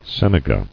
[sen·e·ga]